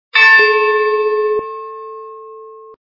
Звук - Небольшие звонки корабельного колокола Звук Звуки Звук - Небольшие звонки корабельного колокола
При прослушивании Звук - Небольшие звонки корабельного колокола качество понижено и присутствуют гудки.